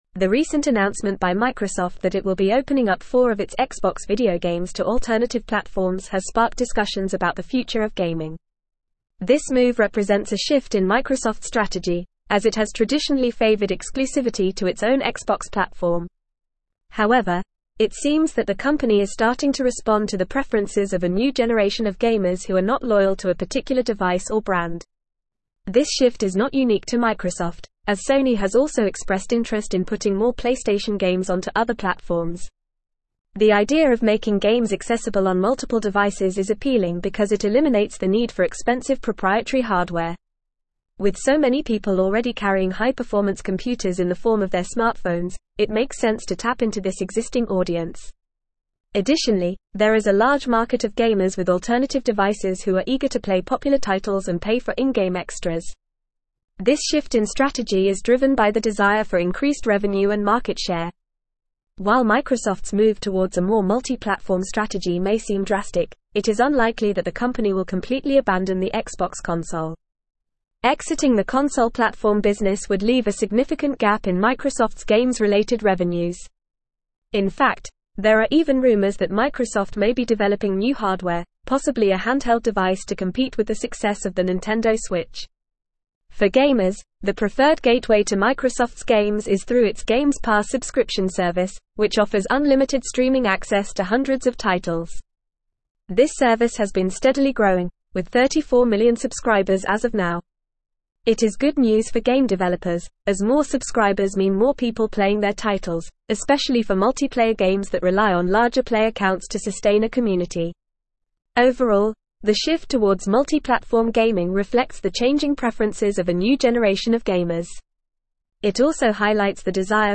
Fast
English-Newsroom-Advanced-FAST-Reading-Microsofts-Xbox-Expands-Gaming-Strategy-to-Multiple-Platforms.mp3